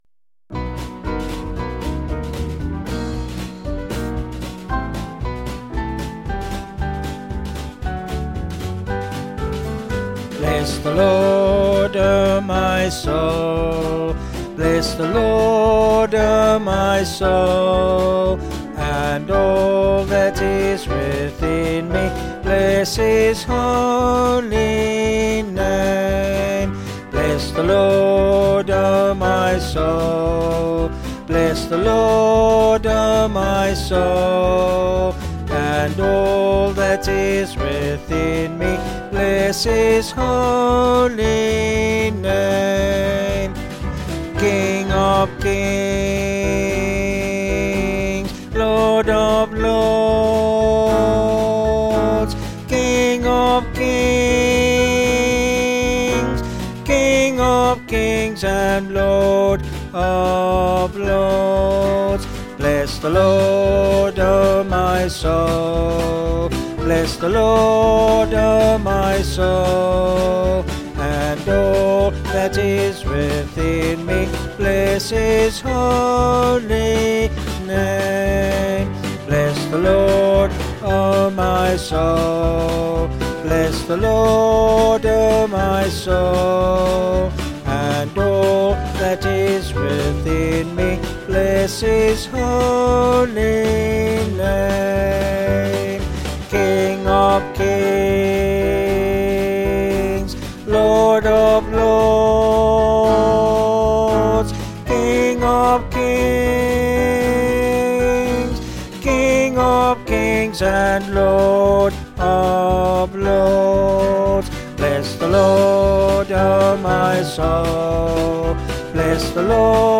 Vocals and Band   264kb Sung Lyrics